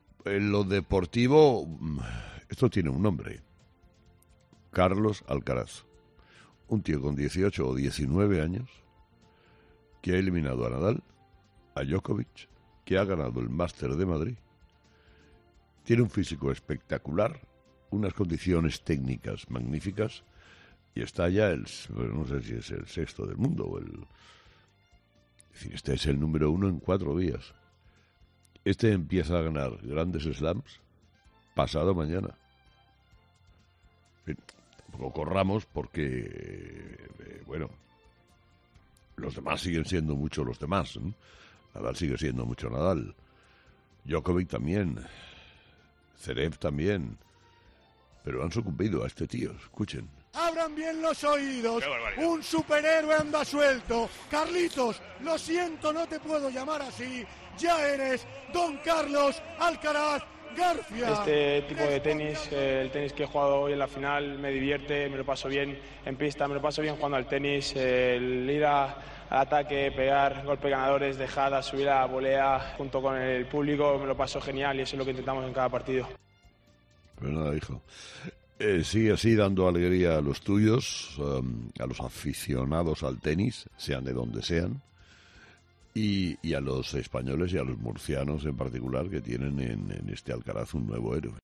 El líder del prime time de la radio en España quiso felicitar al joven tenista murciano en 'Herrera en COPE'